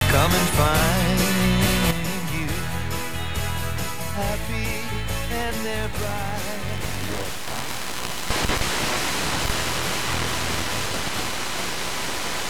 Wenn ich den Audiotransfer zm PC starte ist der Empfang annehmbar (RSSI ist ca. 50dBμV) doch nach ca 4 Sekunden wird der Empfang extrem schlecht und der RSSI Wert sinkt gegen 0.